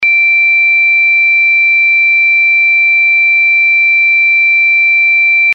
Sirena electrónica